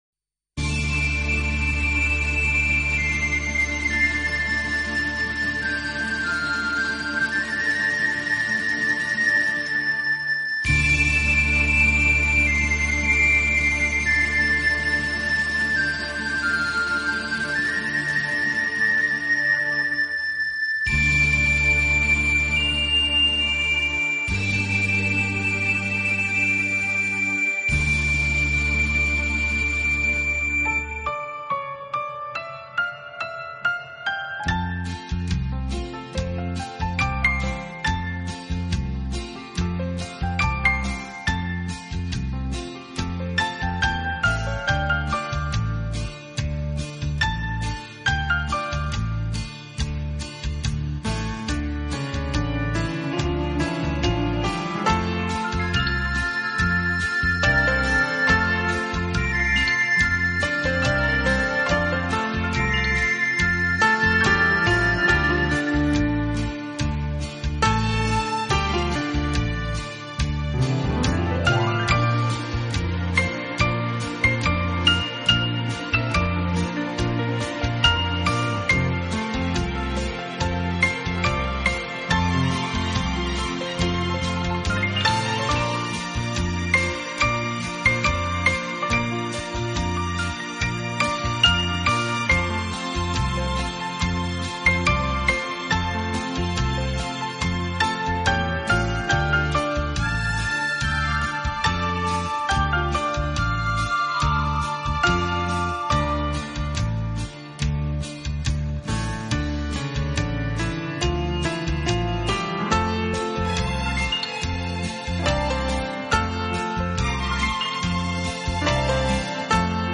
整张专辑全部都是以浪漫情调为主题的钢琴合辑，当熟